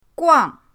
guang4.mp3